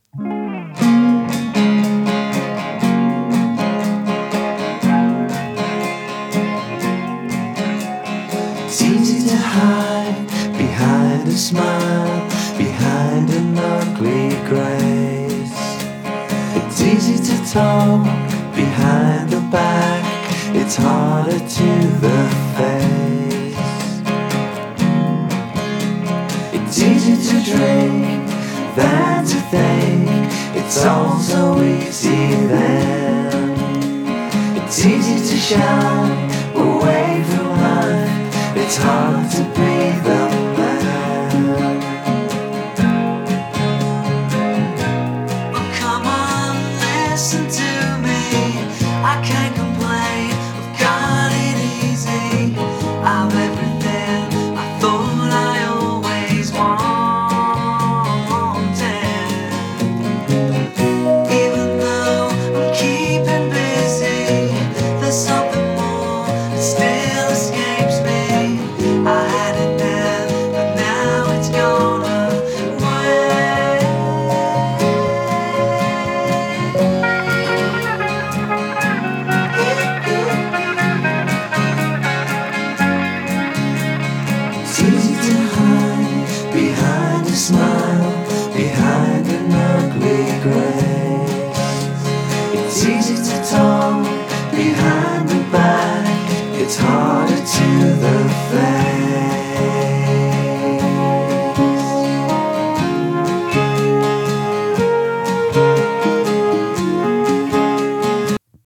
ブリットポップ
試聴はLPからの流用。